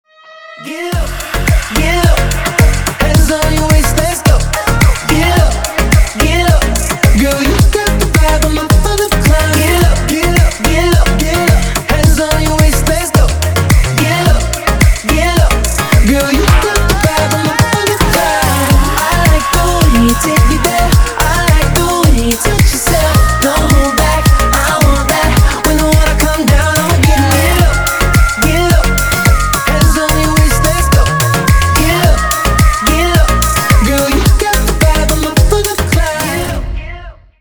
поп
мужской вокал
зажигательные
dance